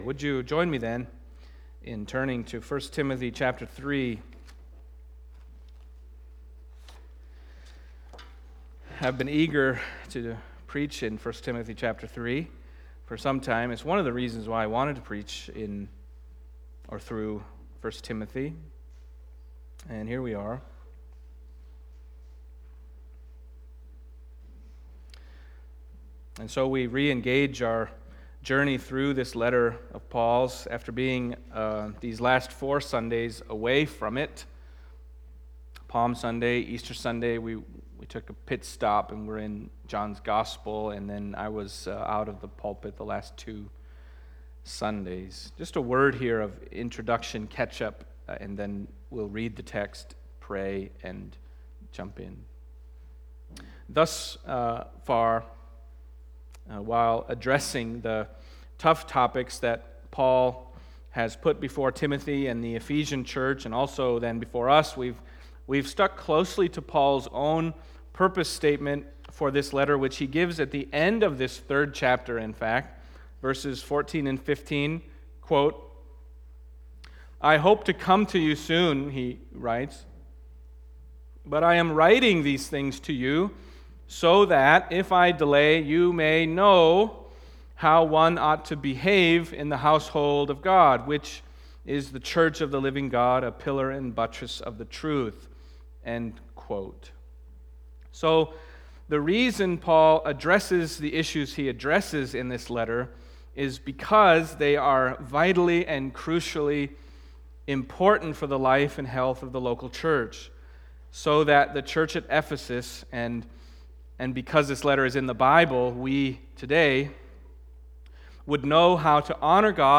Passage: 1 Timothy 3:1-7 Service Type: Sunday Morning